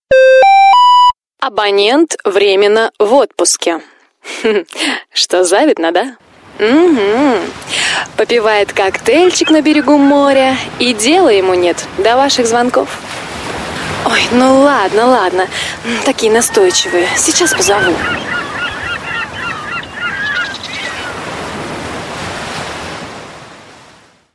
Avtootvеtchik_Abonеnt_vrеmеnno_v_otpuskе
Avtootvеtchik_Abonеnt_vrеmеnno_v_otpuskе.mp3